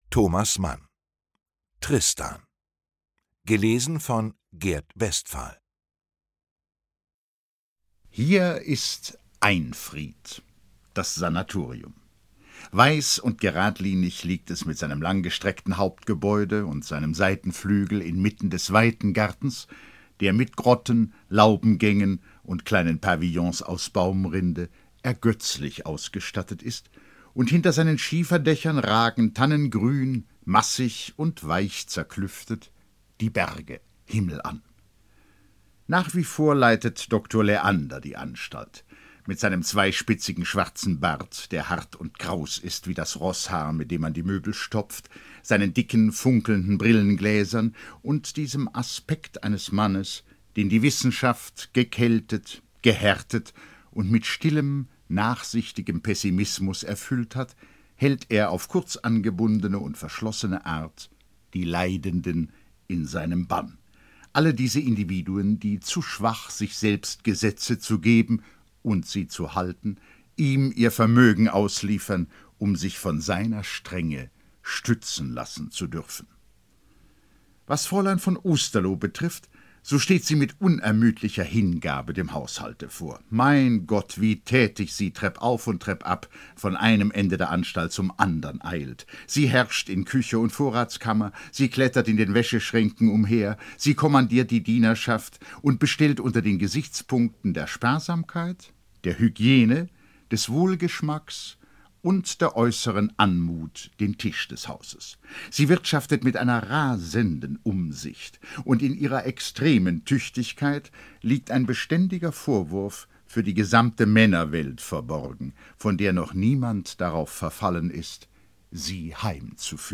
Ungekürzte Lesung mit Gert Westphal (1 mp3-CD)
Gert Westphal (Sprecher)
»Ein virtuoser Humorist.« Deutschlandfunk Kultur